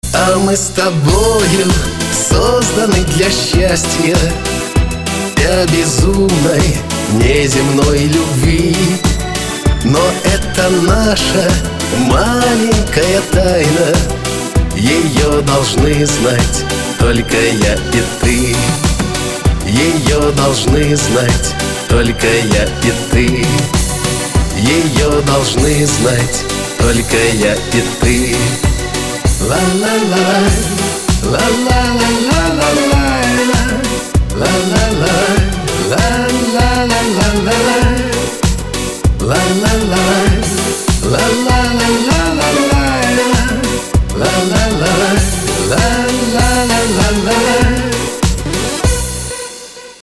• Качество: 256, Stereo
мужской вокал
красивые
русский шансон
добрые